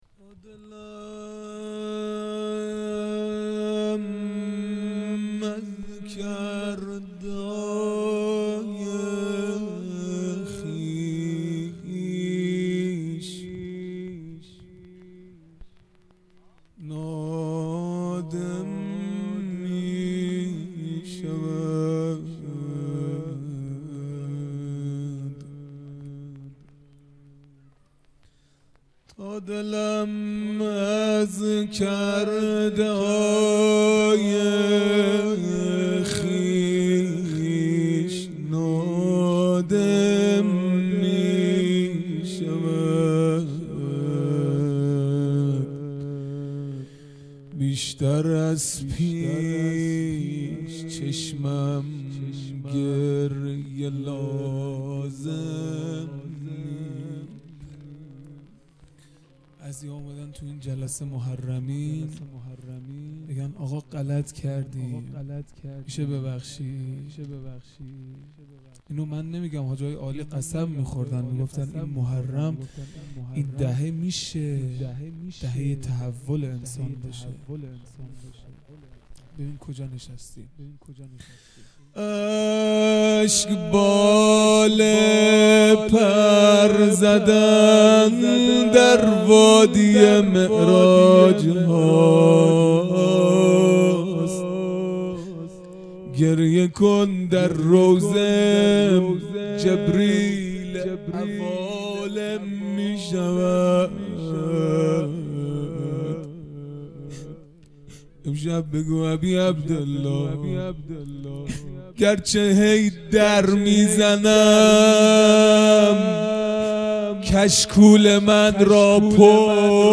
خیمه گاه - هیئت بچه های فاطمه (س) - روضه | تادلم از کرده های خویش نادم میشود